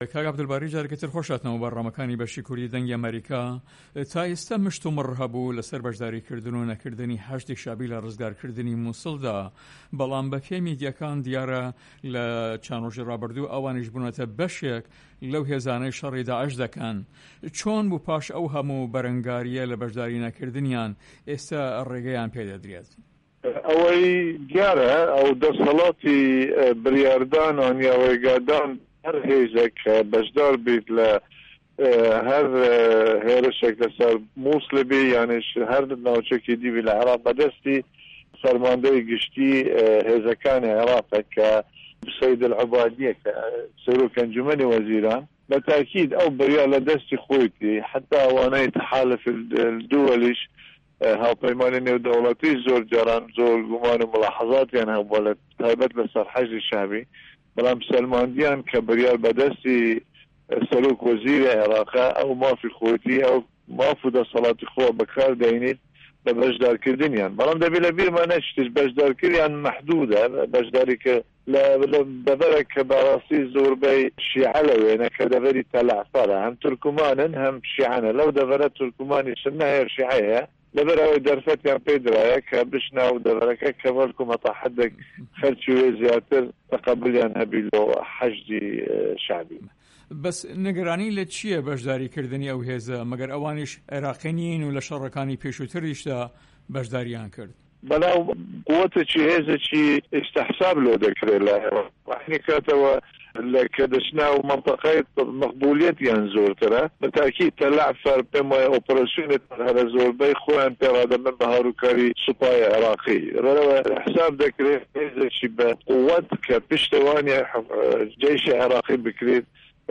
Interview with Abdul Bari Zibari